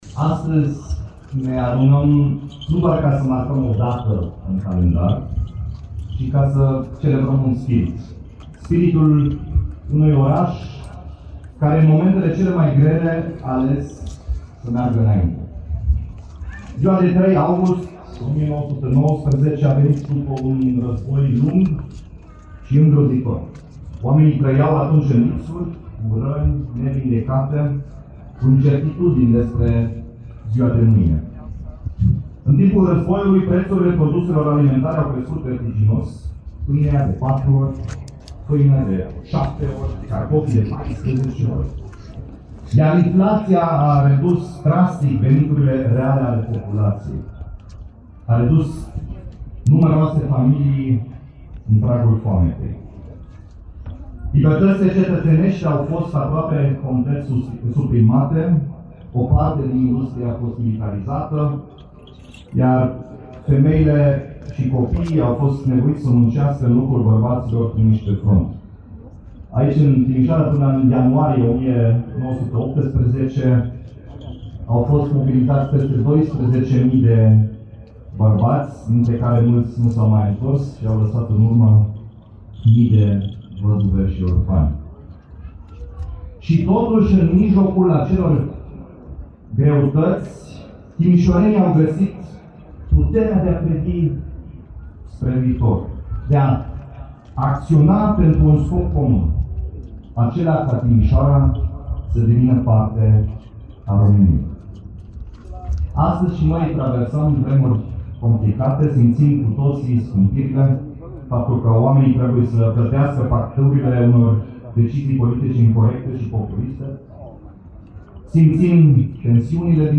Pentru a marca Ziua Timișoarei, sute de oameni au participat, astăzi, la ceremonialul militar-religios din Piața Unirii. Primarul Dominic Fritz i-a îndemnat pe timișoreni să aleagă speranța, în locul resemnării, curajul, în locul izolării, și acțiunea, în locul așteptării.